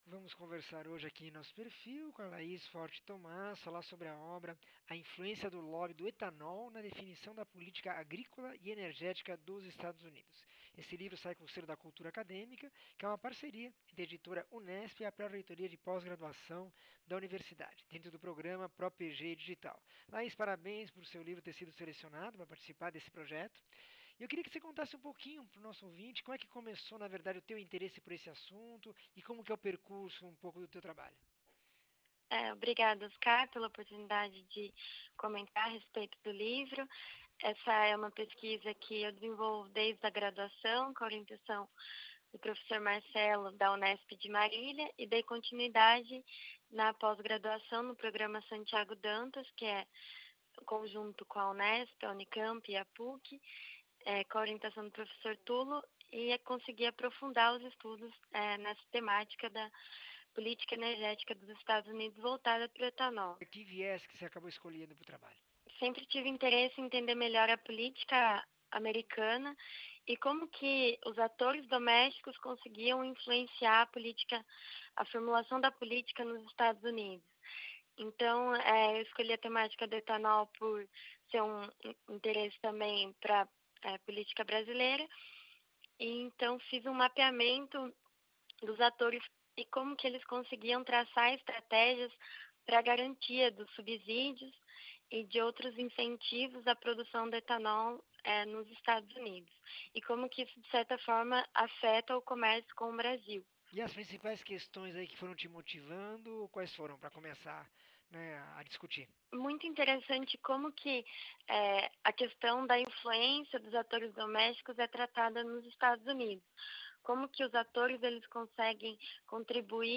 entrevista 1721